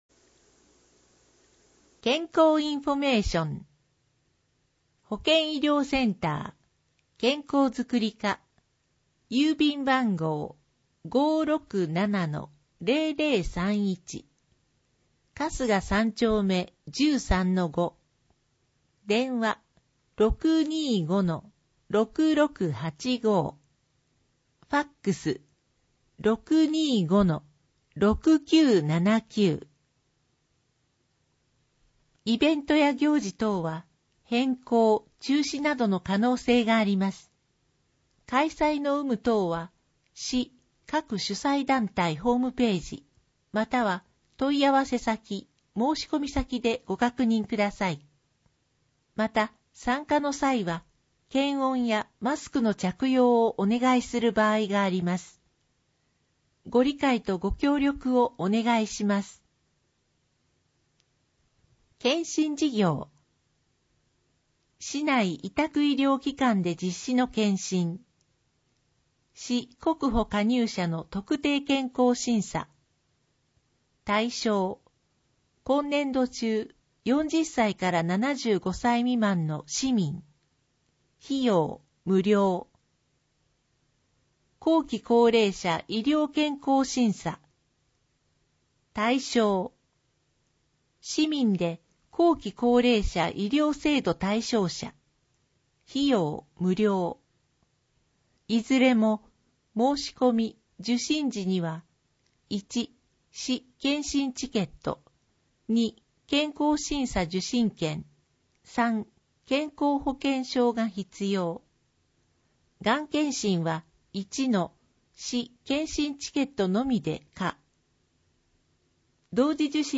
毎月1日発行の広報いばらきの内容を音声で収録した「声の広報いばらき」を聞くことができます。